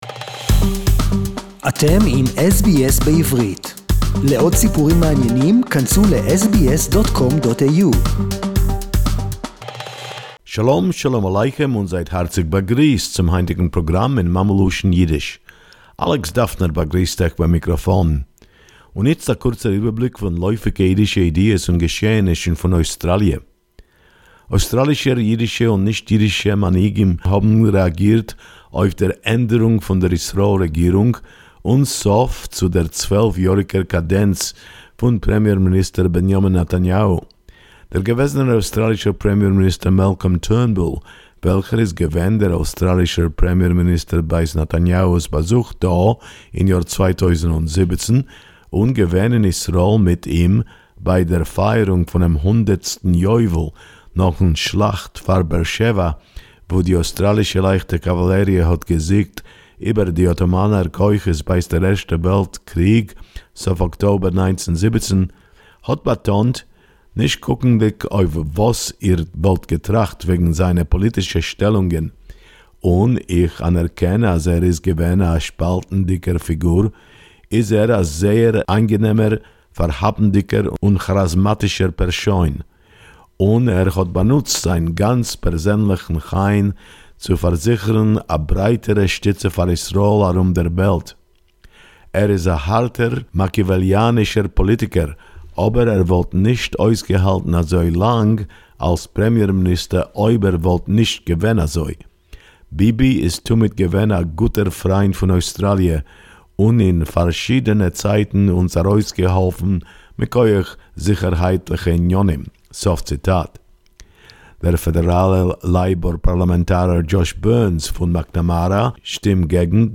Australian politicians & Jewish leaders greeted the new, very diverse Israeli government...SBS Yiddish report